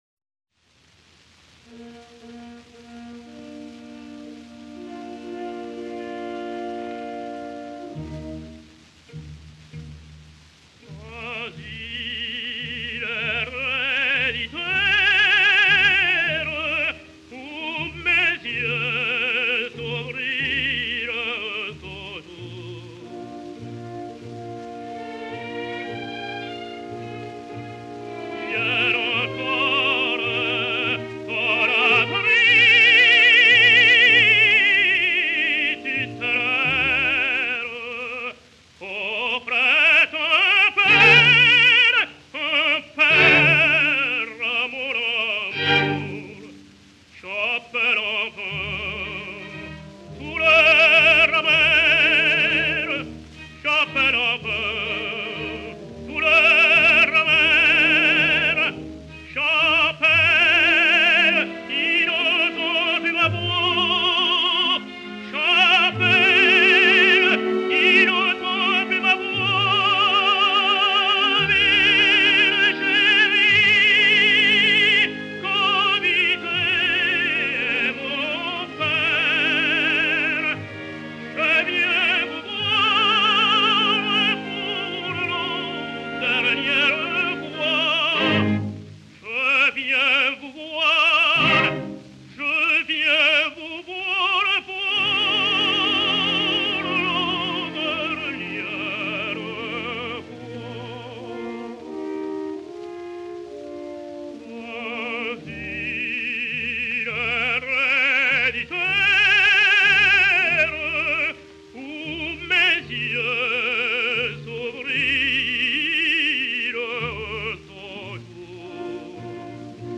Odeon, Paris, 23 January 1933